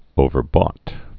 (ōvər-bôt)